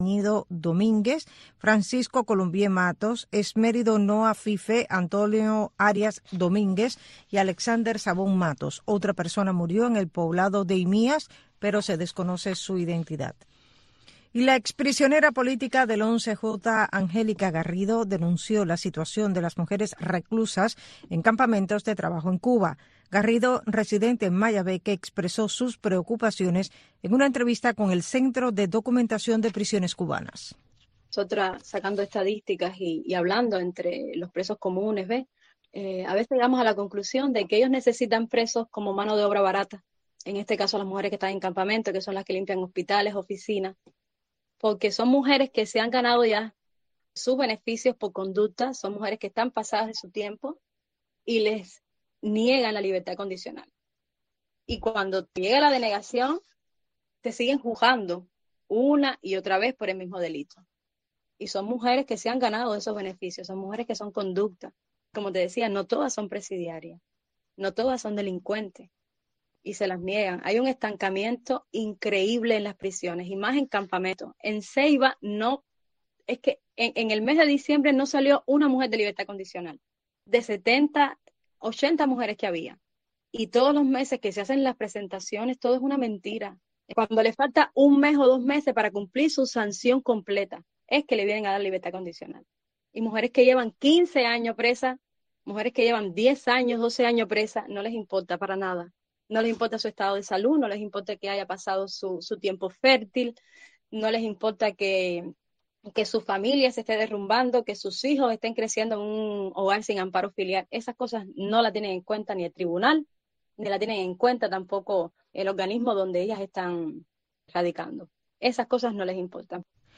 Un espacio radial que va más allá de los nuevos avances de la ciencia y la tecnología, pensado para los jóvenes dentro de la isla que emplean las nuevas tecnologías para dar solución a sus necesidades cotidianas.